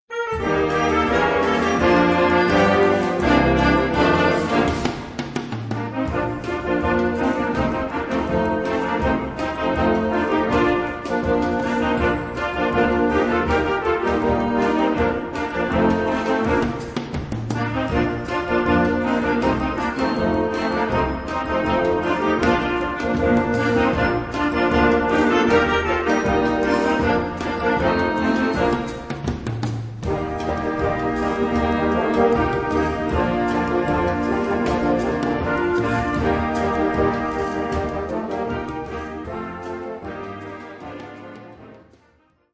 Gattung: Jugendblasorchester
Besetzung: Blasorchester